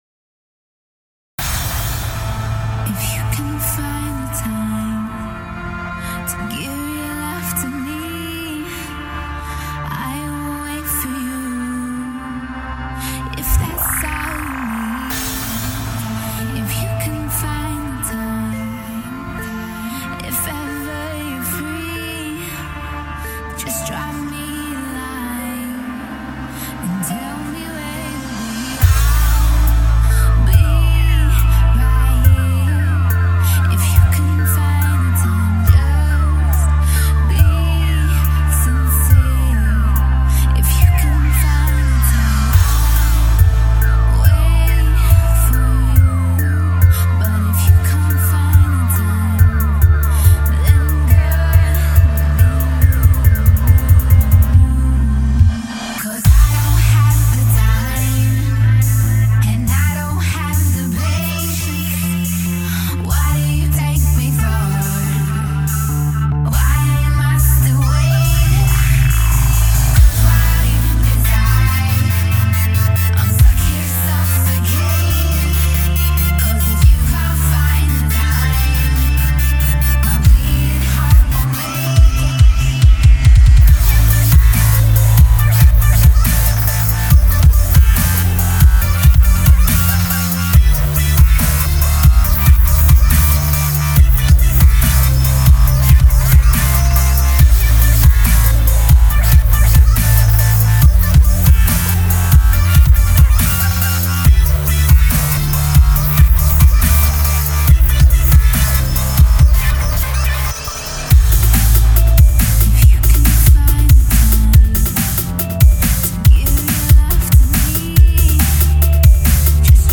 Muzyka_v_mawinu_BASS_Dolbezhka_spcs_global.mp3